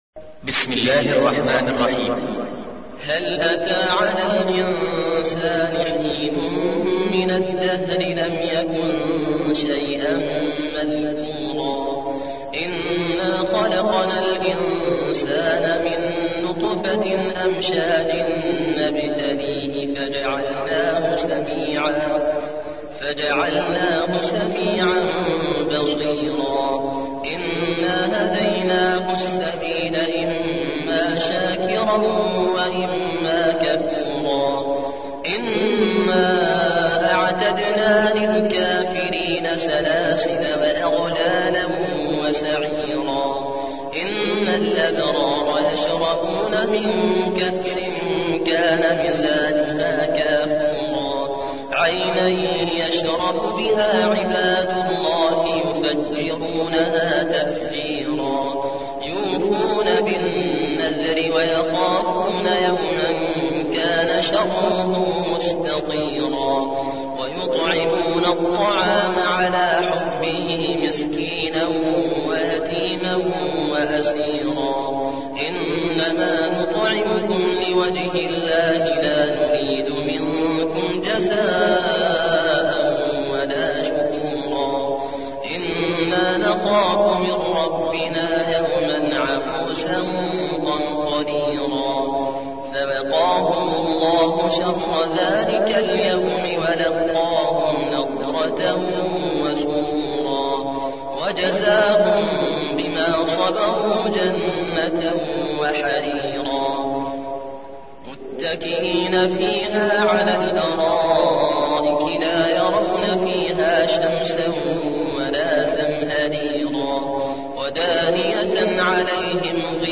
76. سورة الإنسان / القارئ